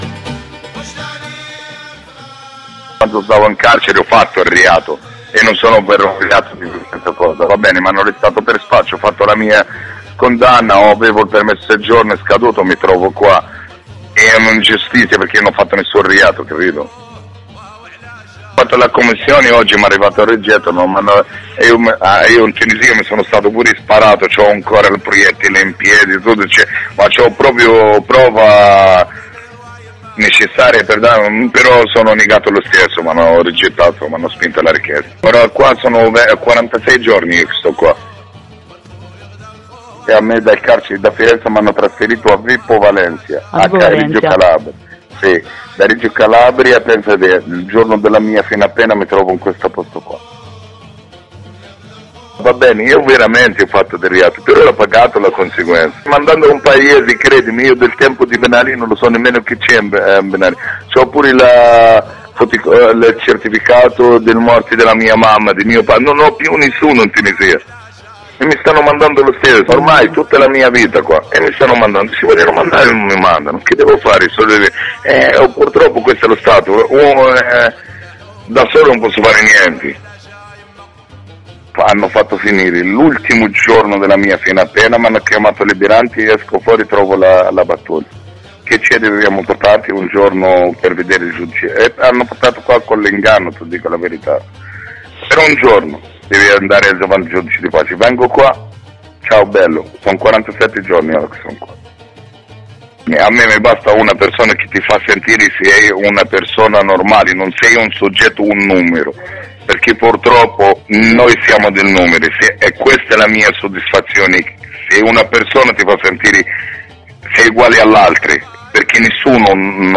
Testimonianze dei reclusi all’interno del CPR di Torino: